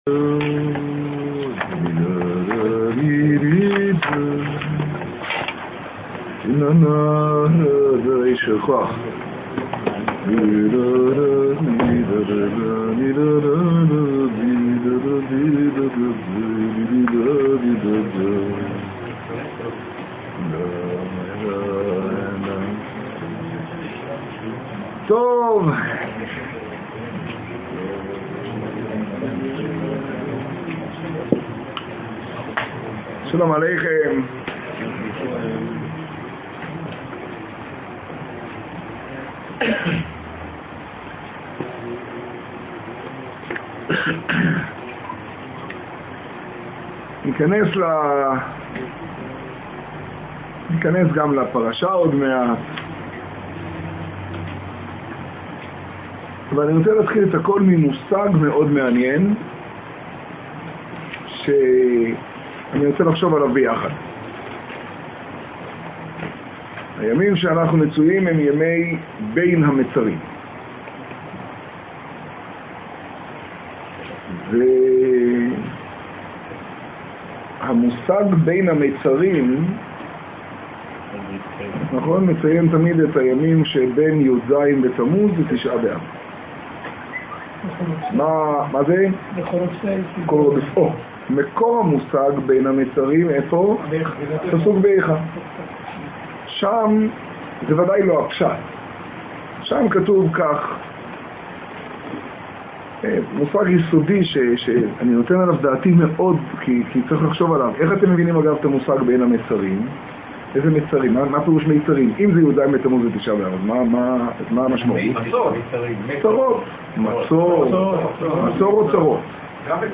השיעור בחצור, פרשת פנחס תשעה.
האזנה קטגוריה: שיעור , שיעור בחצור , תוכן תג: איכה , יז בתמוז , תשעה → השלך על ה' יהבך והוא יכלכלך ישוב ירחמנו יכבוש עוונותינו ←